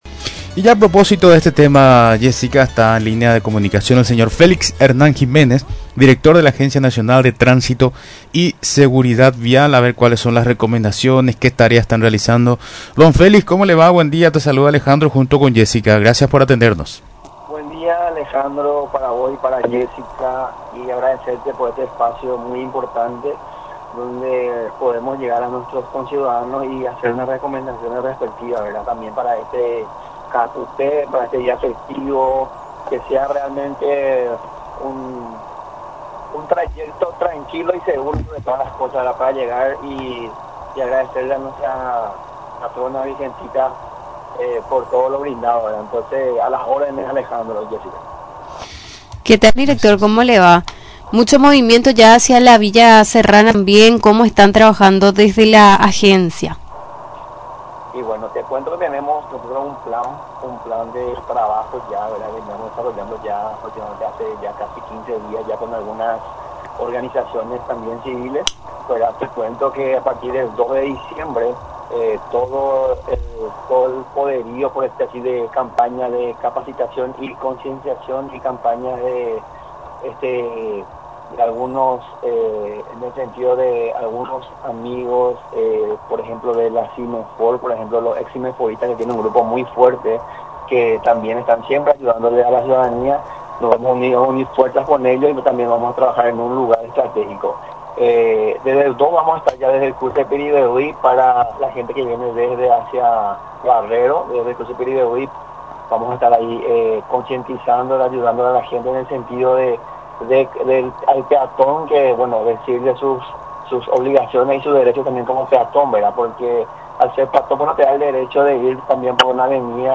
Durante la entrevista en Radio Nacional del Paraguay, el director de la citada institución, Félix Hernán Jiménez, recordó lo que todo conductor debe tener en cuenta para trasladarse hasta la capital del departamento de Cordillera.